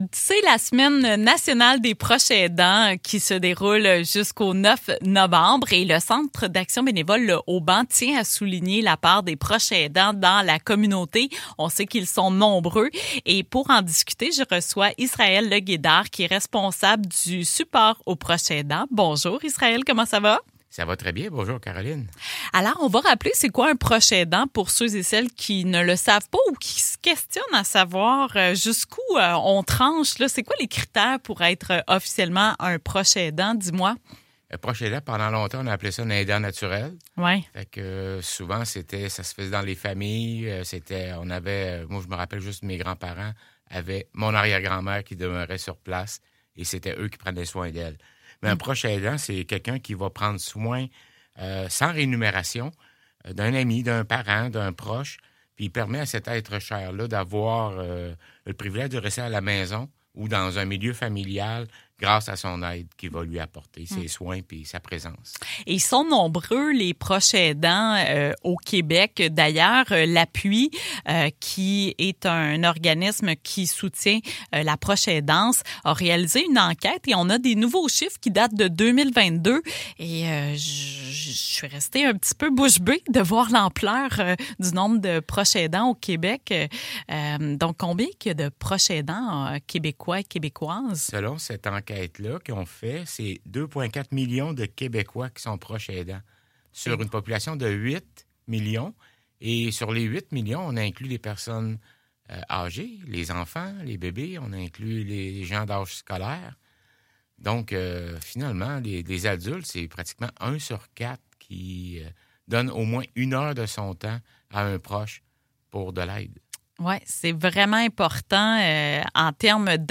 0511-Entrevue-Proches-Aidants.mp3